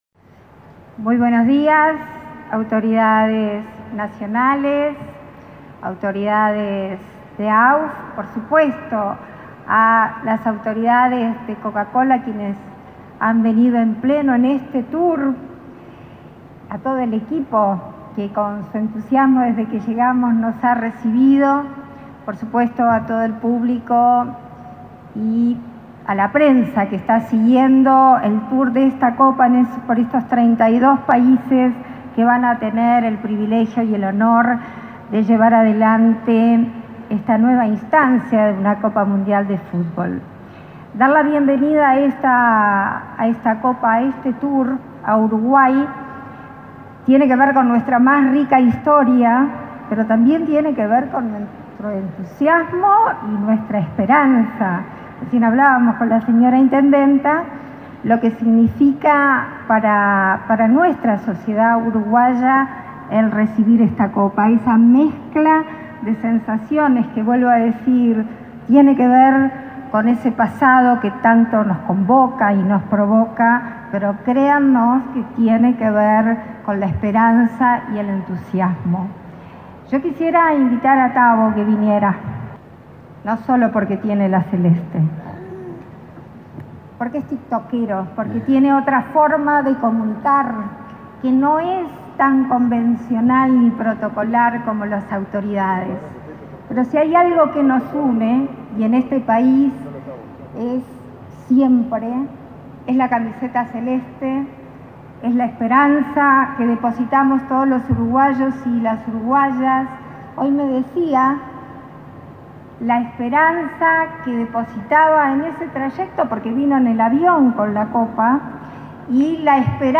Palabras de la presidenta en ejercicio, Beatriz Argimón
La presidenta en ejercicio, Beatriz Argimón, participó en el acto por la quinta edición del tour de la Copa Mundial de la Federación Internacional de